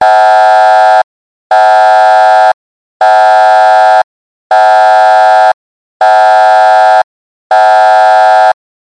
alarm3.mp3